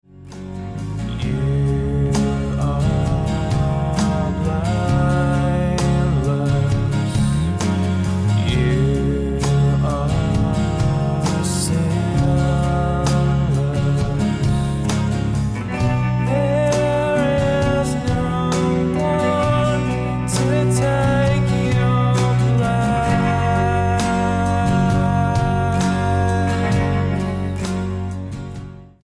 STYLE: Pop
atmospheric guitar-based worship tunes